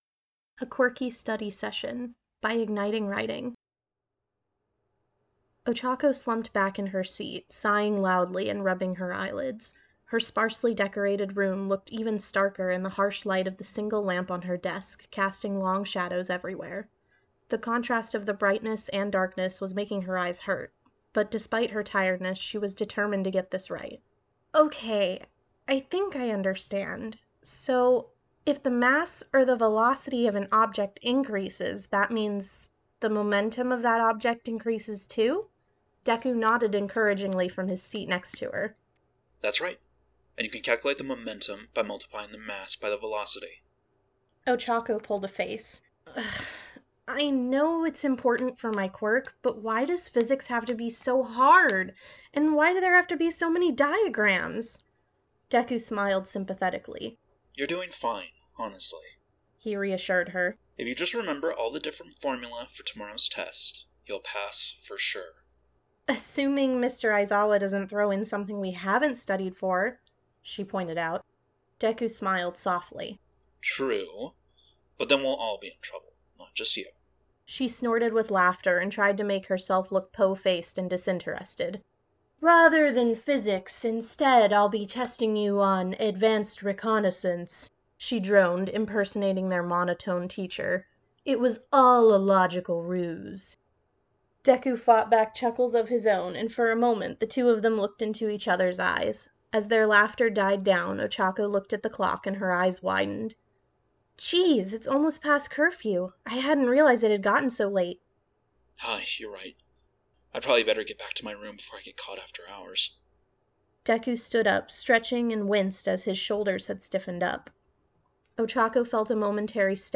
A Quirky Study Session | Podfic
Voice of Izuku Midoriya
Voice of Tenya Iida
Narrator Voice of Ochako Uraraka